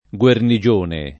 guernigione [ gU erni J1 ne ]